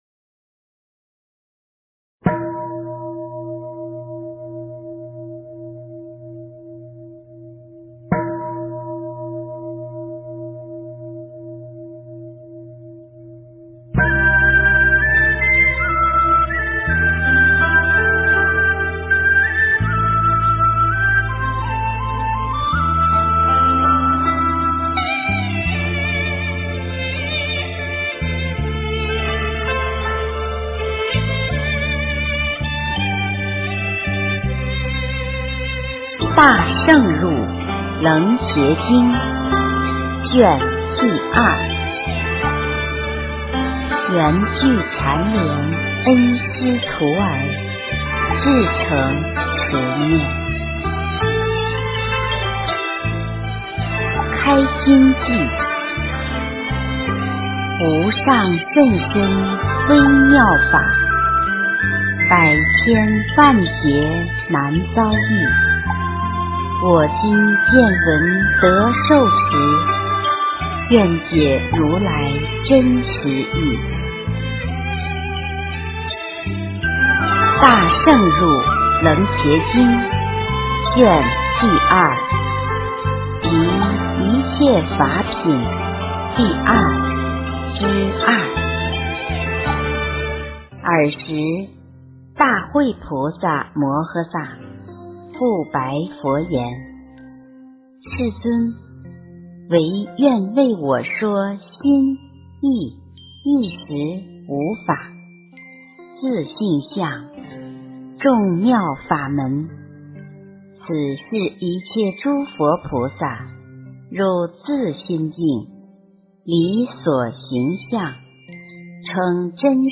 《楞伽经》第二卷上 - 诵经 - 云佛论坛